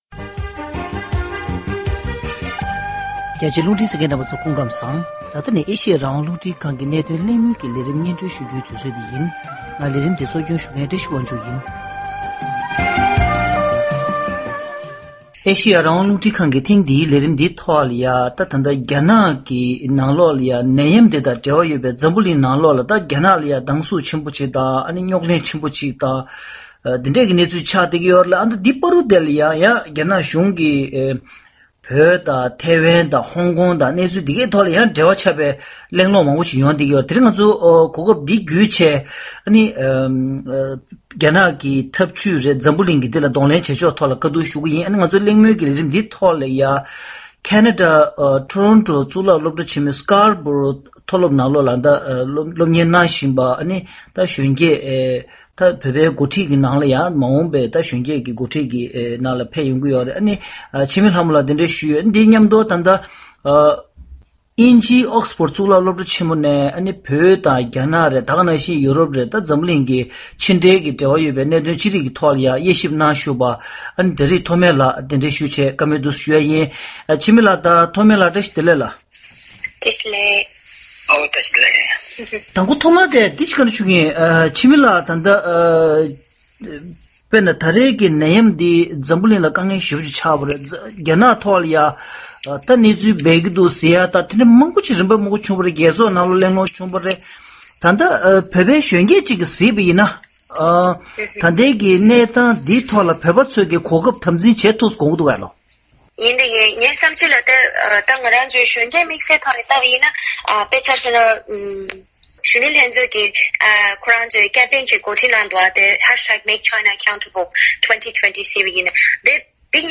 རྒྱ་ནག་གི་ཏོག་དབྱིབས་ནད་ཡམས་དང་འབྲེལ་བའི་འཛམ་གླིང་ནང་གི་དཀའ་རྙོག་དང་བོད་དོན་འཐབ་རྩོད་ཀྱི་ཁ་ཕྱོགས་ཞེས་པའི་བརྗོད་གཞིའི་ཐོག་གླེང་མོལ་ཞུས་པ།